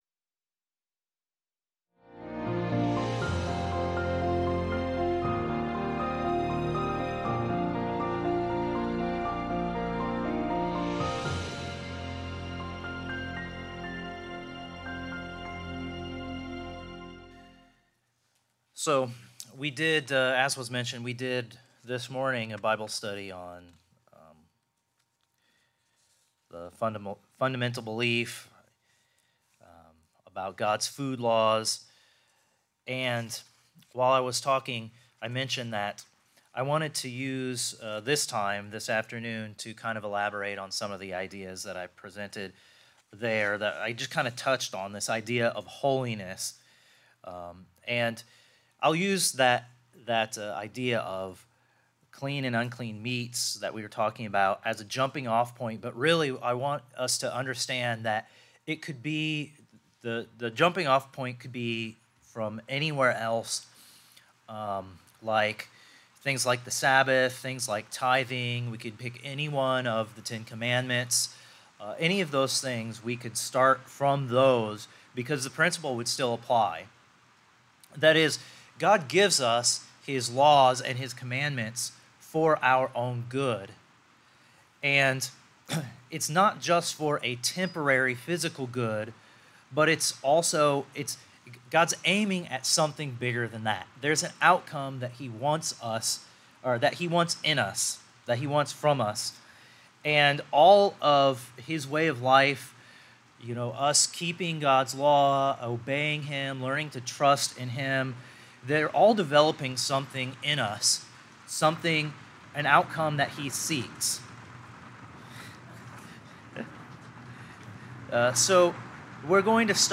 The message 'Be You Holy' is 38 minutes long.